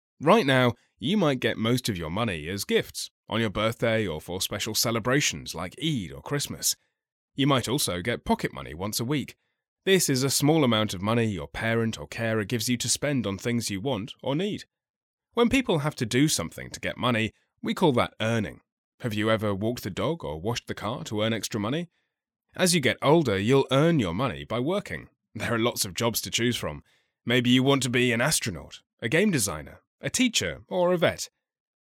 Anglais (Britannique)
Naturelle, Fiable, Chaude, Amicale, Corporative
Corporate